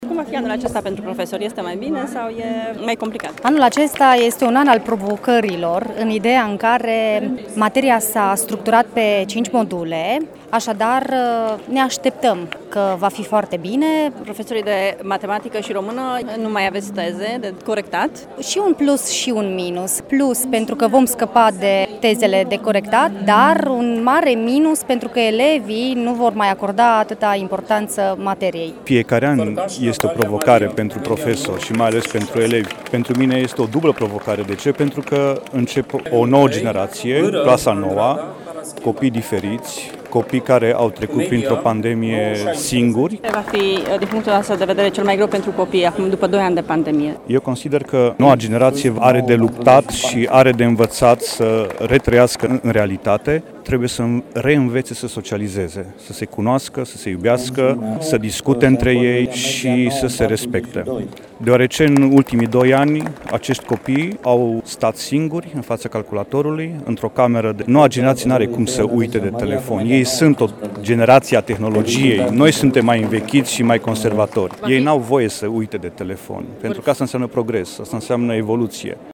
În cadrul festivității de deschidere a noului an școlar la Colegiul Economic ”Transilvania” din Târgu Mureș a avut loc și premierea elevilor care au avut rezultate bune pe parcursul anului trecut școlar: